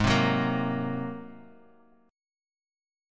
G#9 Chord
Listen to G#9 strummed